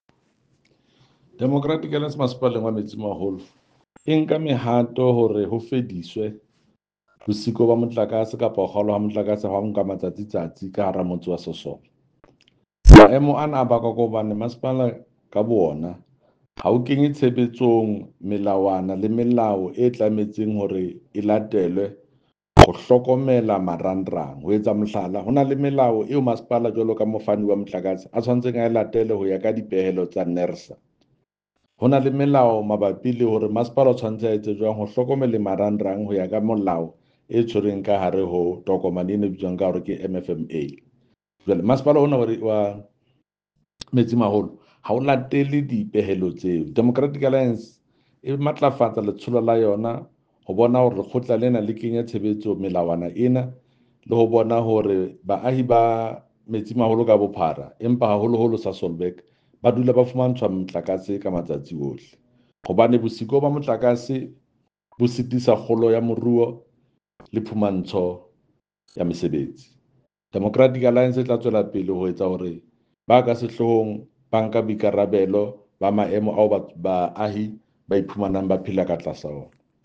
Sesotho soundbite by Jafta Mokoena MPL with picture here